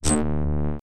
Magnet_surge_02.wav